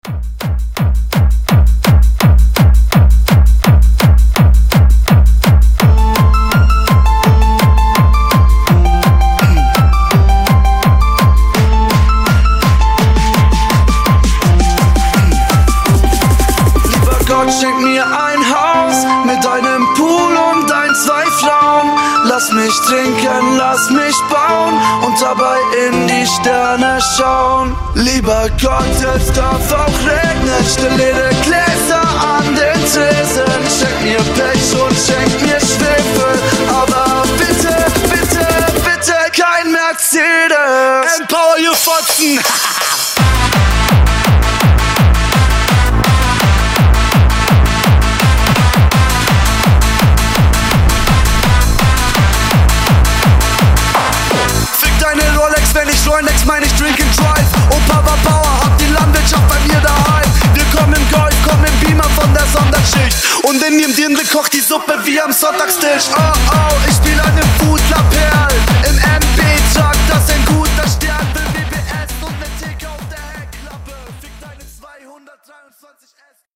Genre: EDM
Dirty BPM: 167 Time